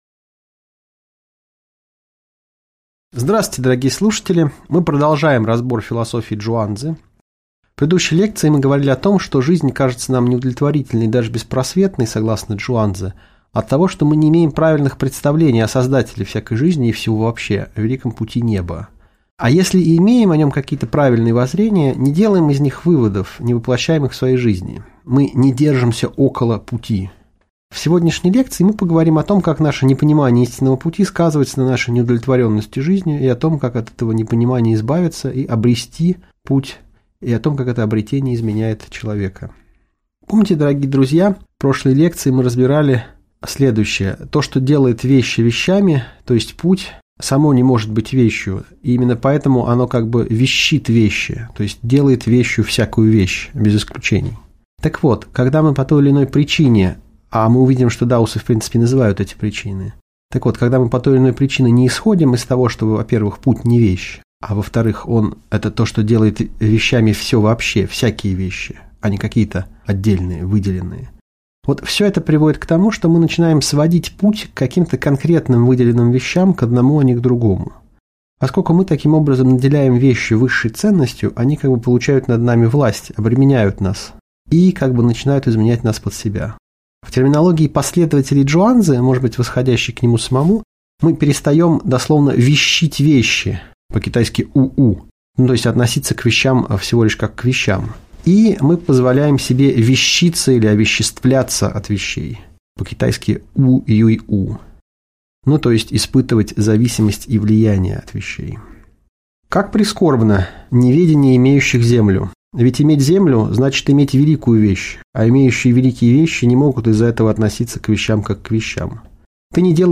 Аудиокнига Лекция «Чжуан-цзы. Часть 2» | Библиотека аудиокниг